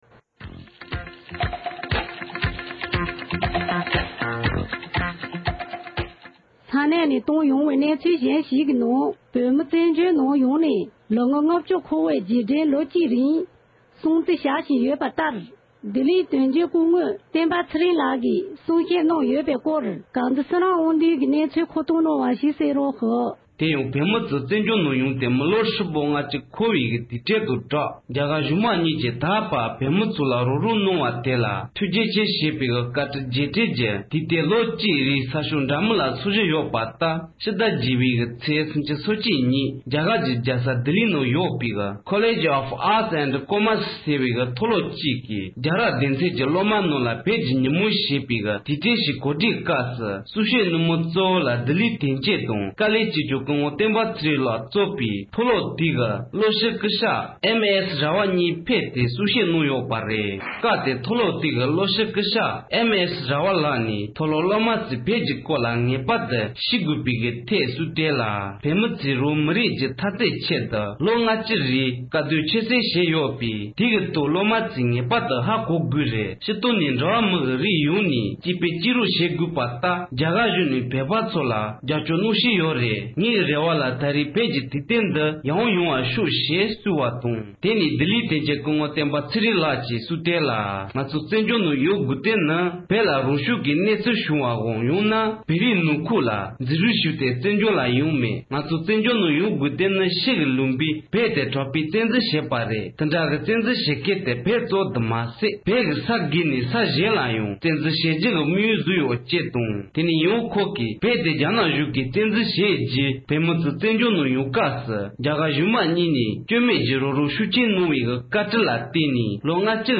རྒྱ་གར་རྒྱལ་ས་ལྡི་ལི་ནང་བོད་ཀྱི་ཉིན་མོ་ཞེས་དུས་དྲན་གོ་སྒྲིག་གནང་བའི་ཐོག་བཀའ་བློན་བསྟན་པ་ཚེ་རིང་མཆོག་གིས་གསུང་བཤད་གནང་ཡོད་པ།